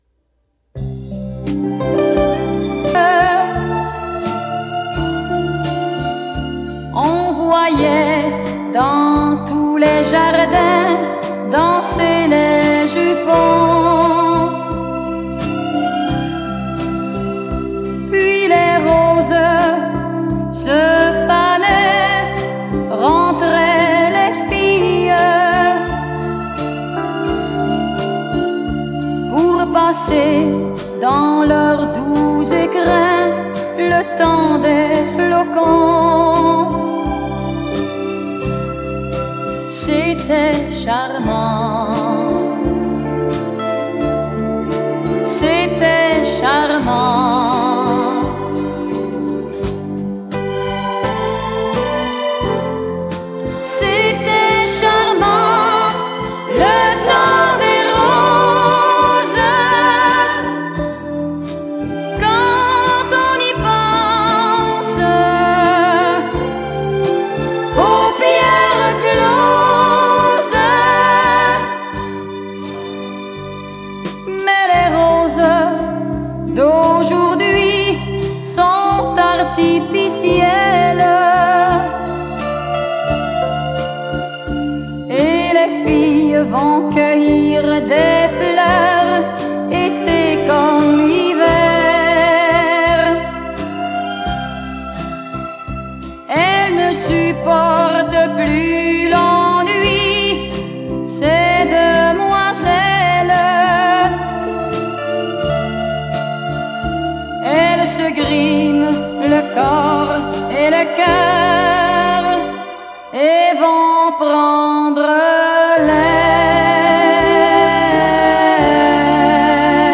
Son: stéréo
Enregistrement: Studio St-Charles à Longueuil